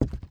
Footstep_Wood 03.wav